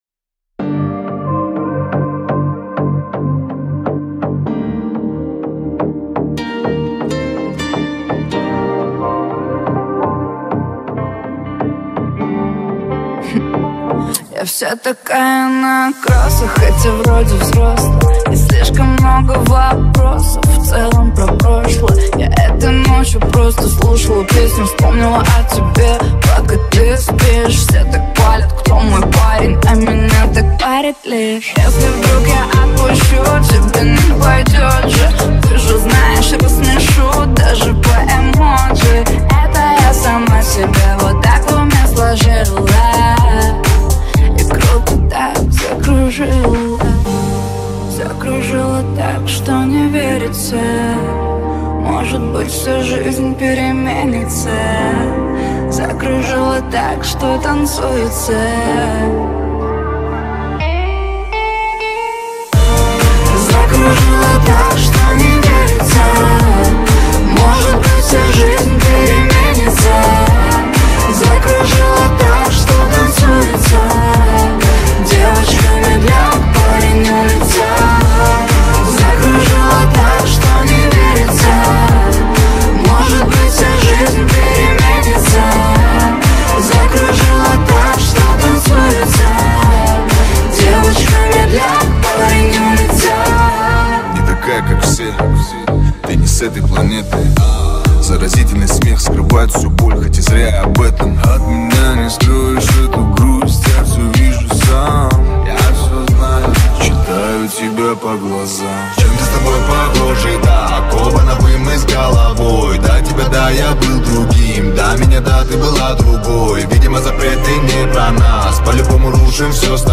• Категория: Новые ремиксы